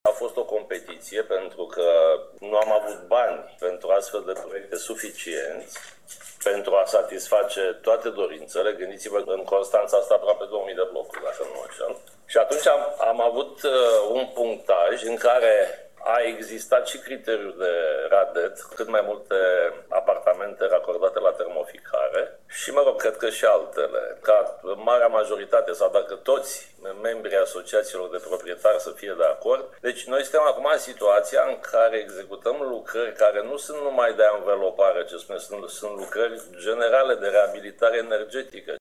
Primarul Vergil Chițac a declarat că selecția a fost făcută pe baza unui punctaj, iar lucrările vor presupune mai mult decât schimbarea izolației exterioare: